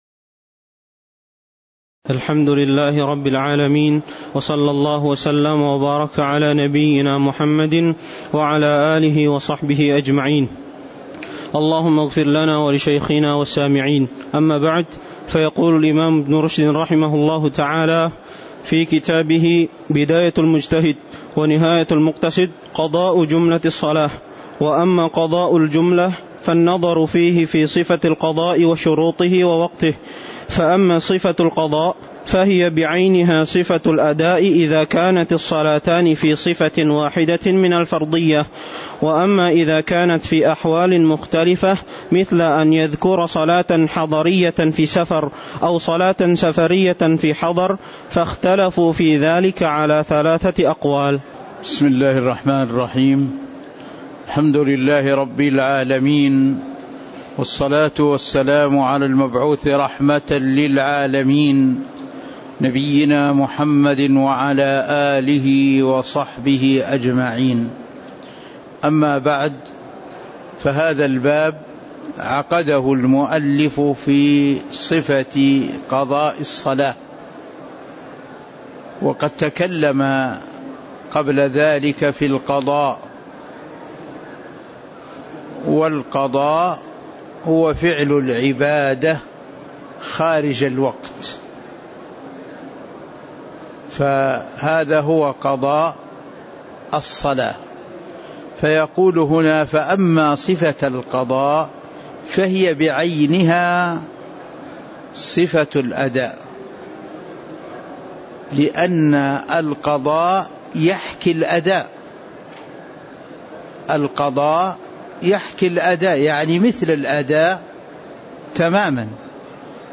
تاريخ النشر ٦ ربيع الأول ١٤٤٤ هـ المكان: المسجد النبوي الشيخ